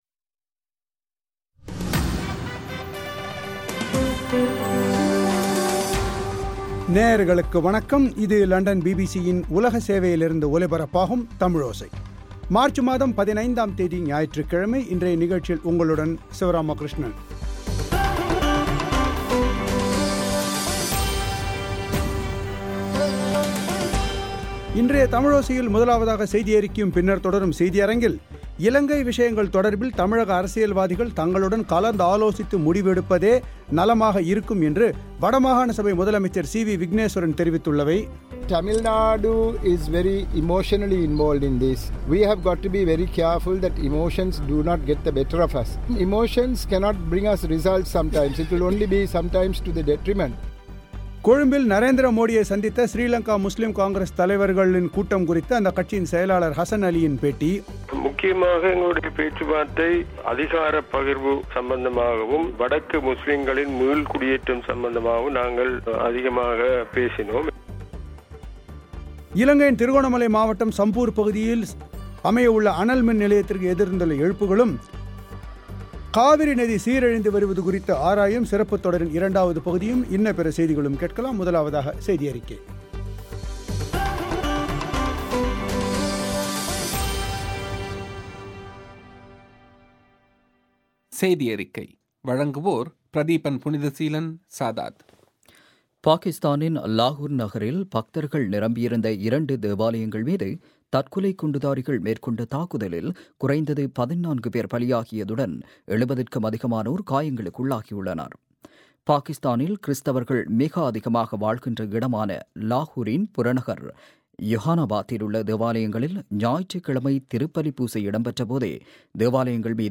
கொழும்பில் இந்தியப் பிரதமர் நரேந்திர மோடியைச் ஸ்ரீலங்கா முஸ்லிம் காங்கிரஸ் தலைவர்கள் சந்தித்து பேசியுள்ளது பற்றி அக்கட்சியின் பொதுச் செயலர் ஹசன் அலியின் பேட்டி